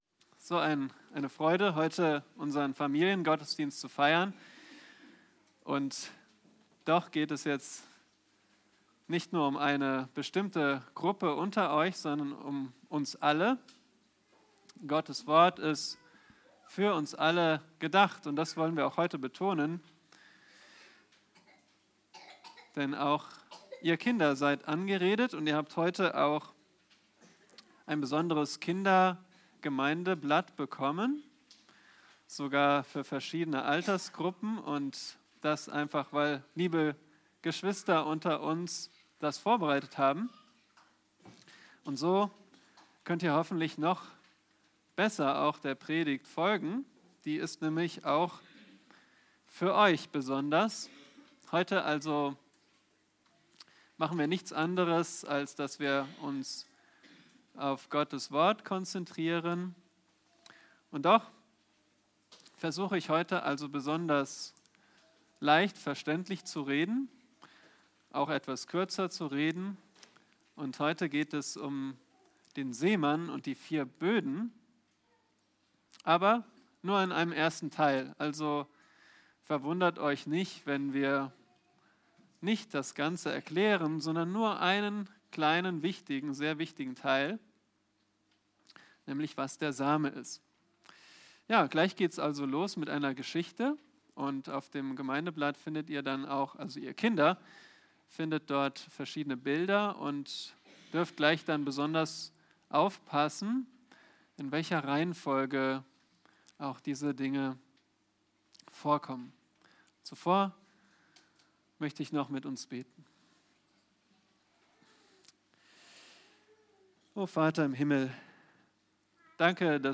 Weitere Predigten